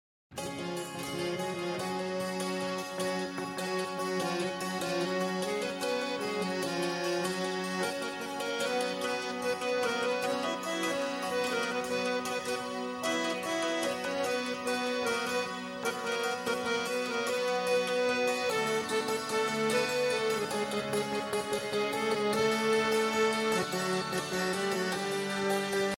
Фисгармония
Звук производят проскакивающие металлические язычки. В совокупности с возможностью переключения регистров, звучание становится сходным с мягко звучащим органом.